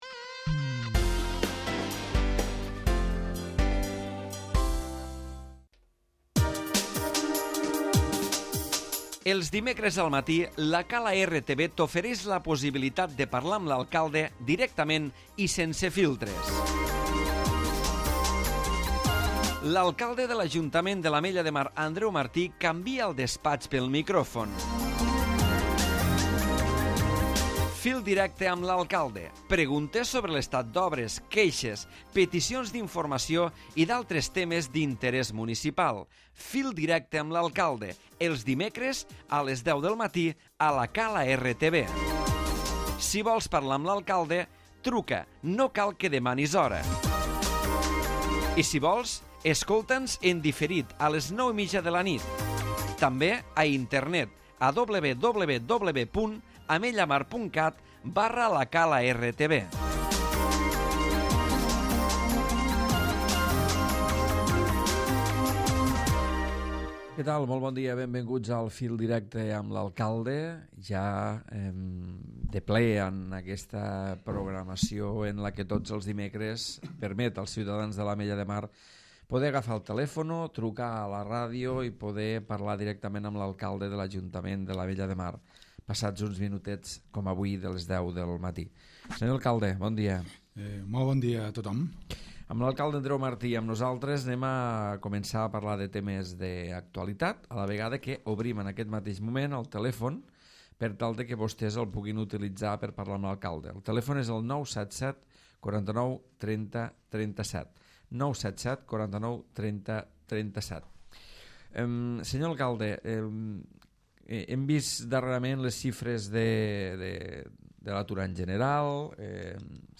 L'Alcalde de l'Ajuntament de l'Ametlla de Mar, Andreu Martí, parla del dia a dia municipal i se sotmet a les trucades dels oients.